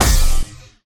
polygon_explosion_energynuke.wav